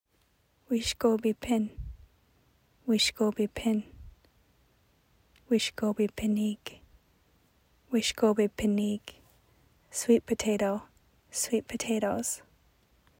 Anishinaabemowin pronunciation: "weesh-ko-bi-pin (eeg)"